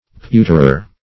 Search Result for " pewterer" : The Collaborative International Dictionary of English v.0.48: Pewterer \Pew"ter*er\, n. One whose occupation is to make utensils of pewter; a pewtersmith.